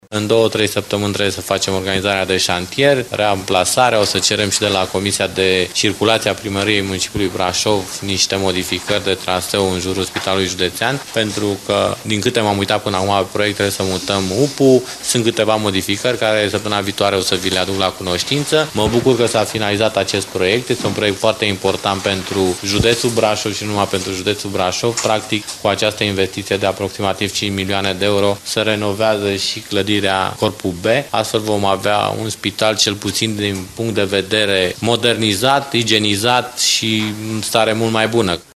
Lucrările de modernizare a Corpului B al Spitalului Județean Brașov vor costa 19,41 de milioane de lei iar firma care a câștigat licitația de executare a lucrărilor este SC Romliant Construct SRL din Baia Mare, spune vicepreședintele CJ Brașov, Adrian Gabor.